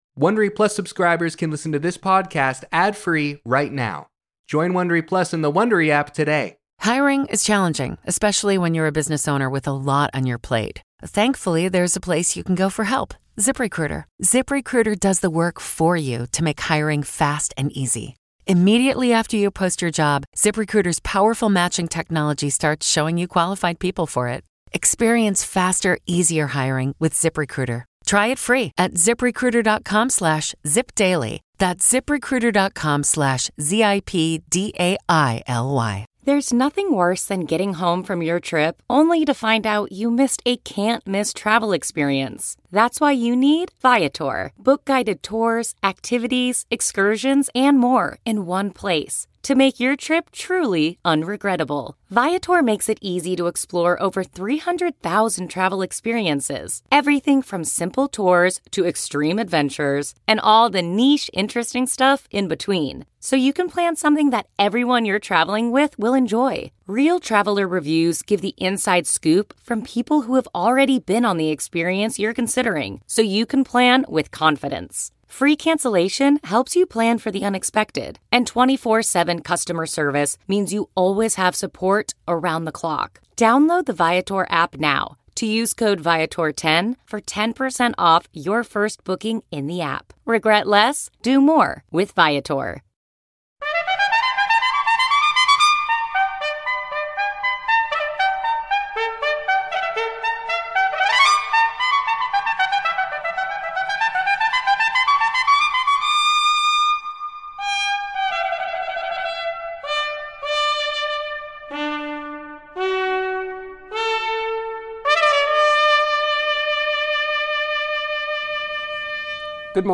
Guest host: John Dickerson. In our cover story, Ben Tracy reports on how landslides and fires closing California's scenic Highway 1 are affecting residents and businesses in Big Sur. Also: In her first broadcast interview, Supreme Court Justice Ketanji Brown Jackson talks with Norah O'Donnell; and Kelefa Sanneh profiles actress, writer, director and producer Natasha Lyonne.